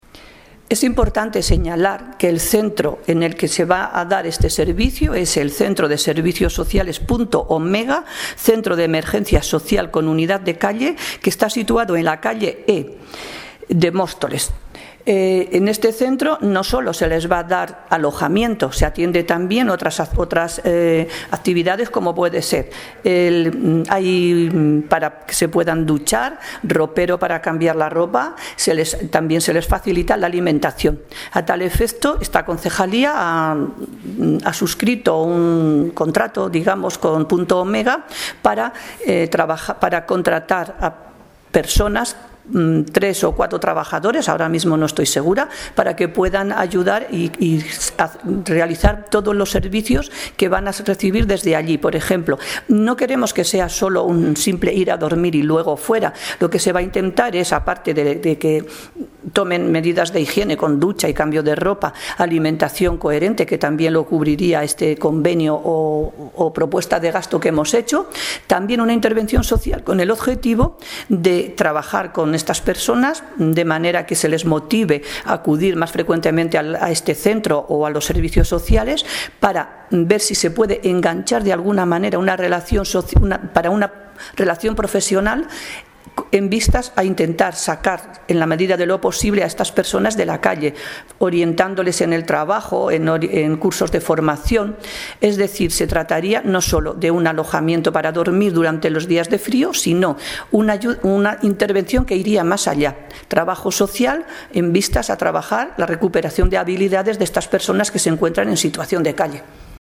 Audio - Ana María Rodrigo (Concejala de Bienestar Social, Sanidad e Igualdad) Sobre Ola de Frio